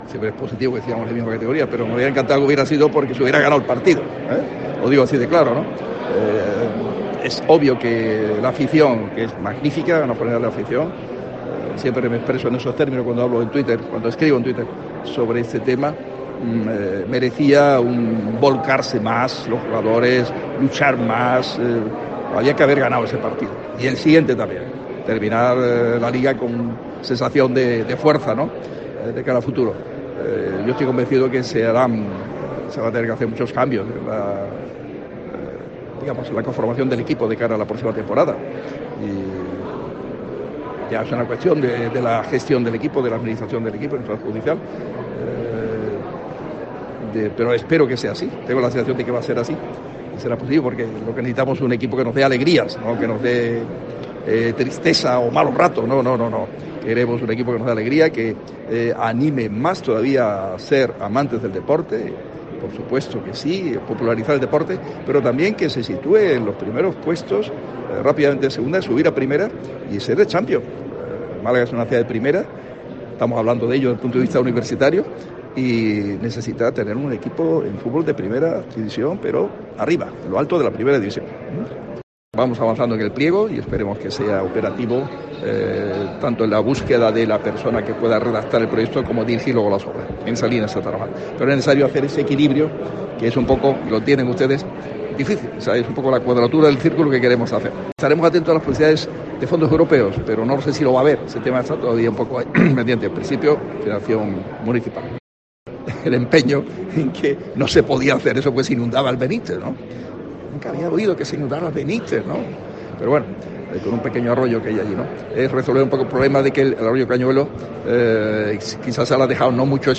"Ha habido que modificar el proyecto", ha recordado De la Torre tras ser cuestionado por los periodistas, al tiempo que ha incidido en que "estaba en un punto concreto y pasa a otro espacio, hacia la plaza de los Filipenses, en un sitio en la calle Jorge Lamothe".